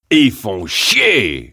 Tags: Duke Nukem sounds quotes Dukenukem Ultimate Ultime francais france repliques sons bruits voix phrases